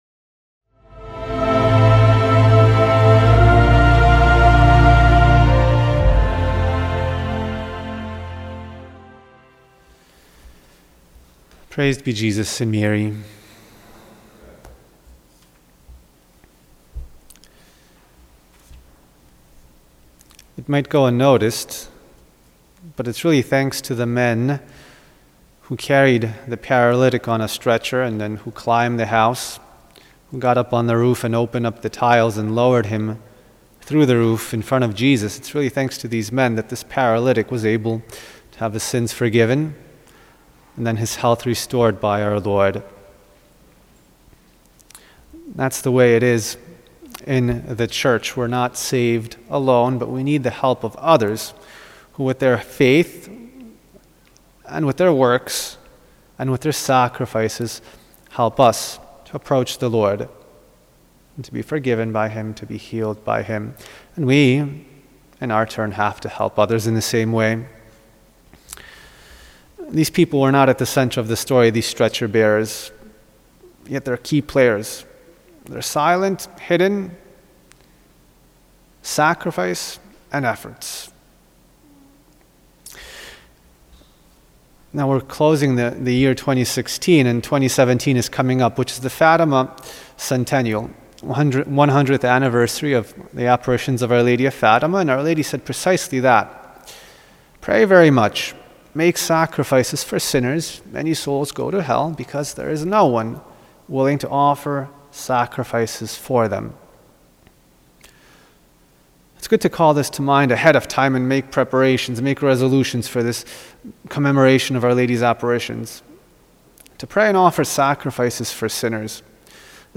Homily
Mass: Monday in the 2nd Week in Advent -